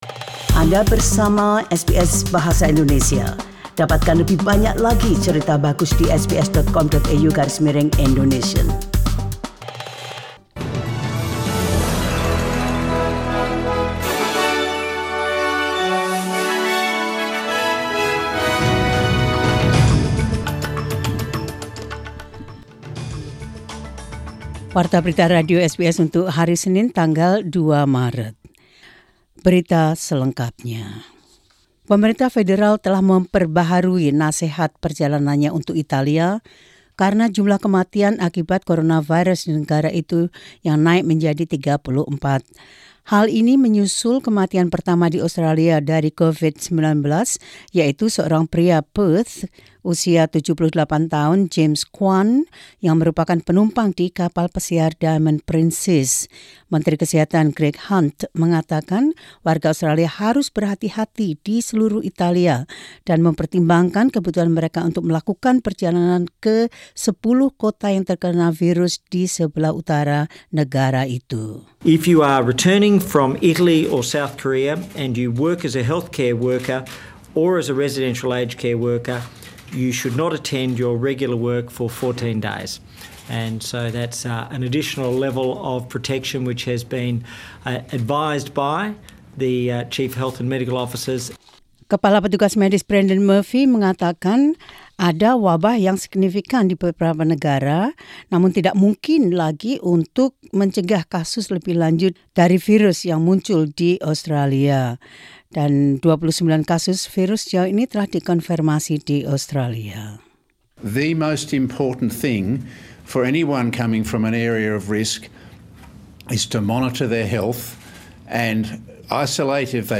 SBS Radio News in Indonesian 2 Mar 2020.